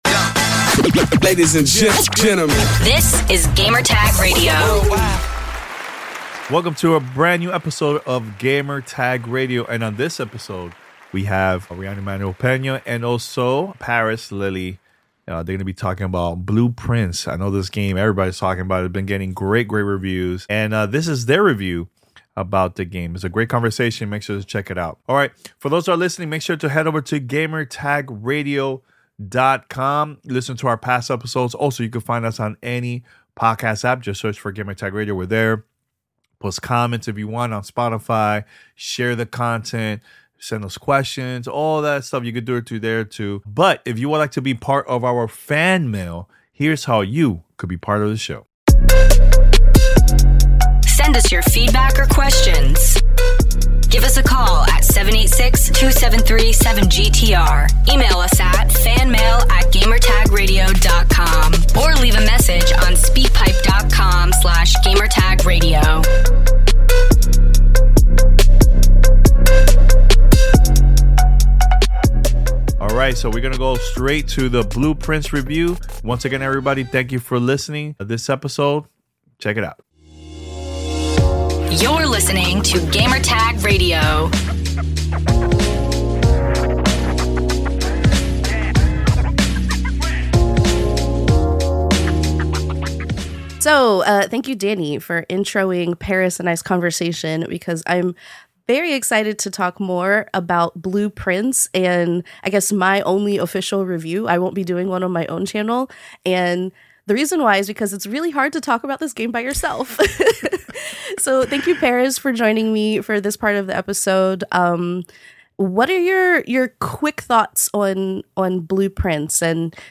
Tune in for an in-depth review and lively discussion!